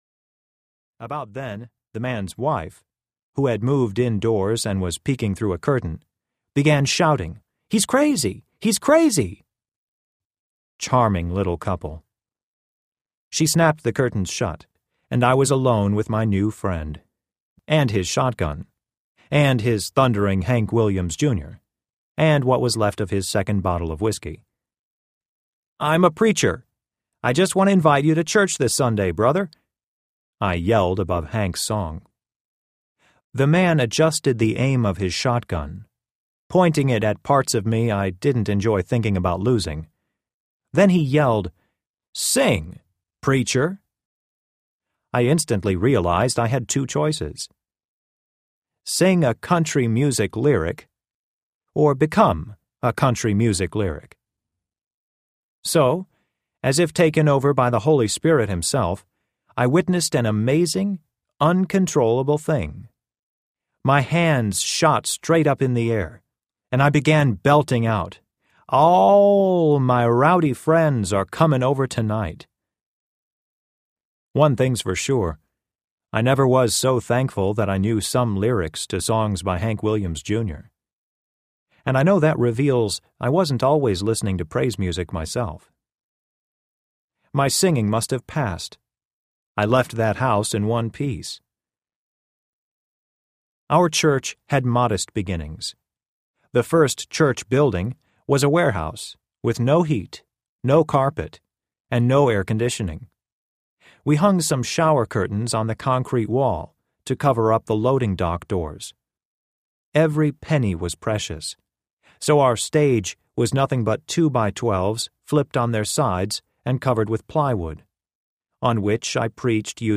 The Necessity of an Enemy Audiobook
Narrator
6.05 Hrs. – Unabridged